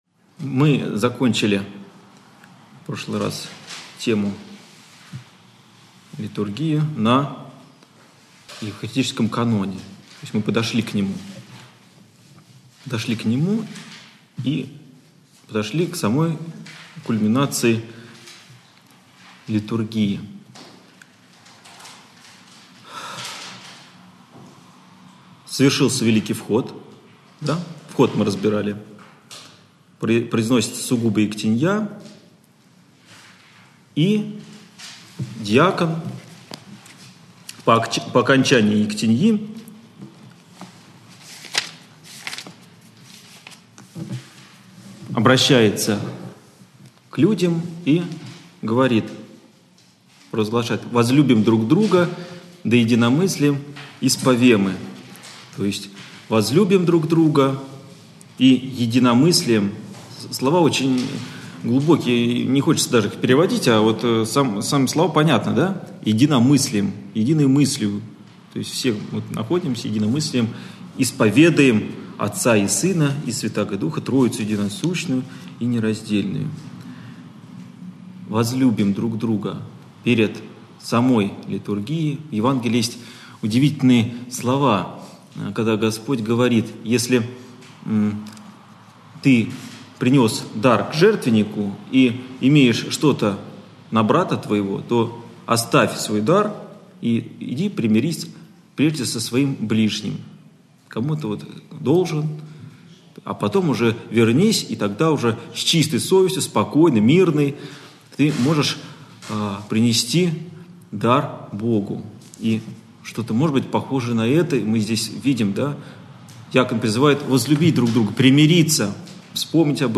Общедоступный православный лекторий 2013-2014